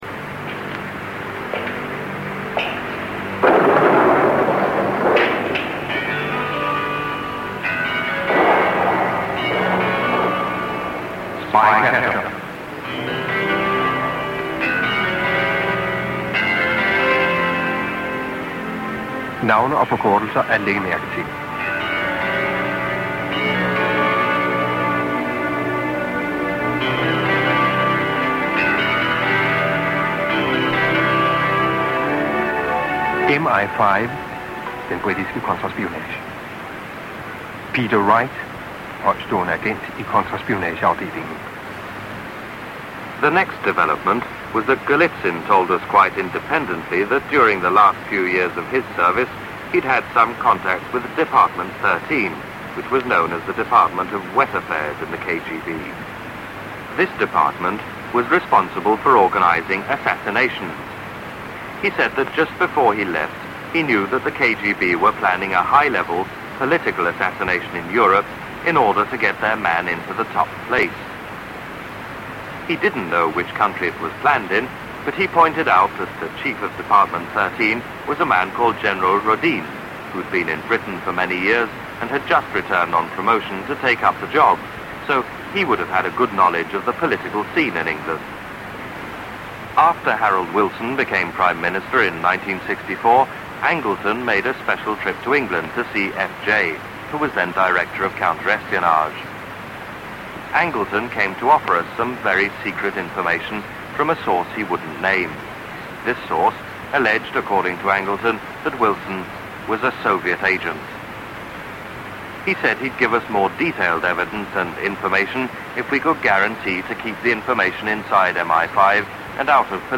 Such was the book's notoriety that a Danish radio station serialised readings and broadcast them across Europe. This transmission was recorded on 14 August 1987.